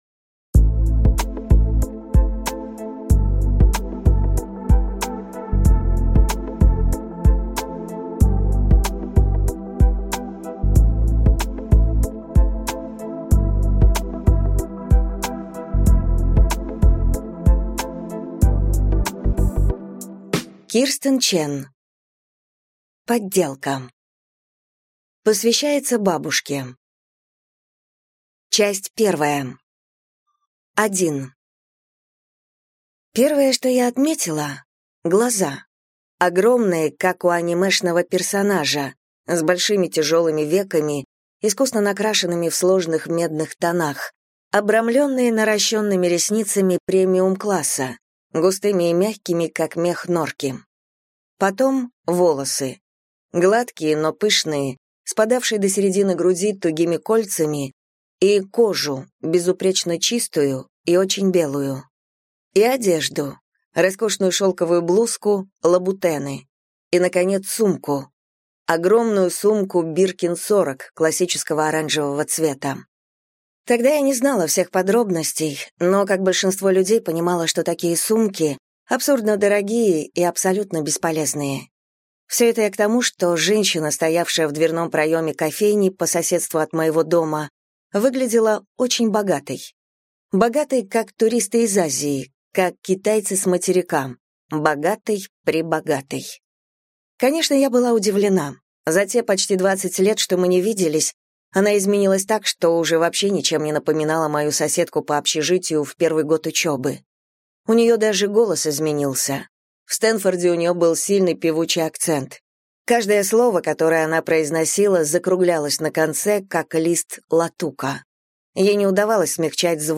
Аудиокнига Подделка | Библиотека аудиокниг